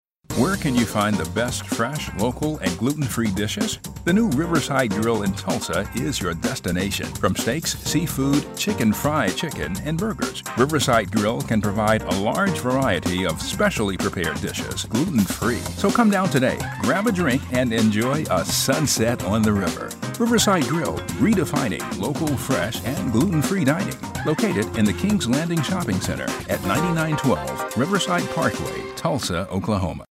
VOICE ACTOR DEMOS